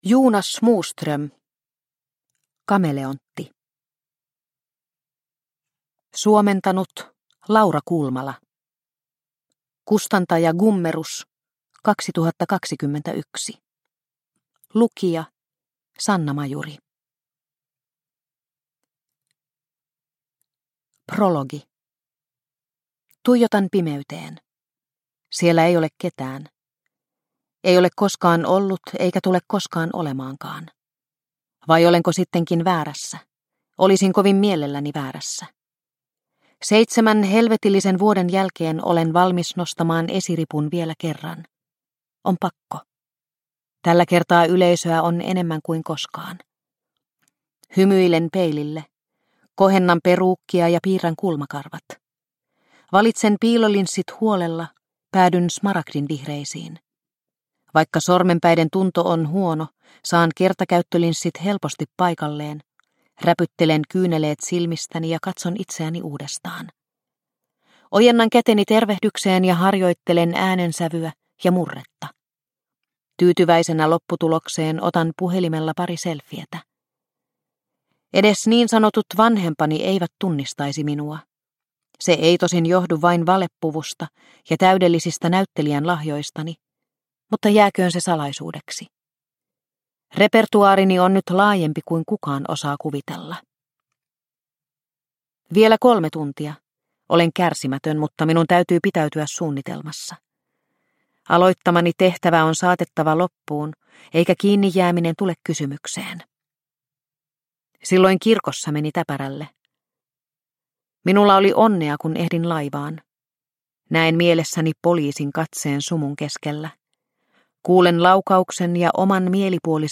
Kameleontti – Ljudbok – Laddas ner